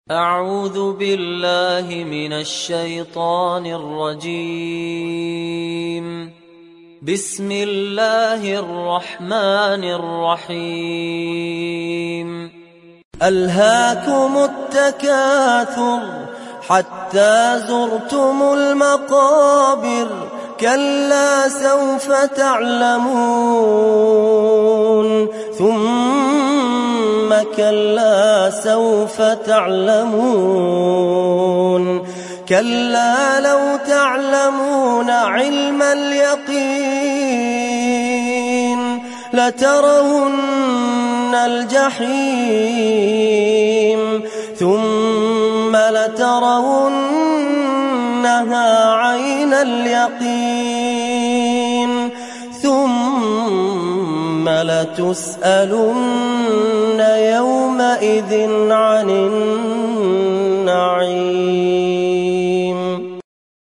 تحميل سورة التكاثر mp3 بصوت فهد الكندري برواية حفص عن عاصم, تحميل استماع القرآن الكريم على الجوال mp3 كاملا بروابط مباشرة وسريعة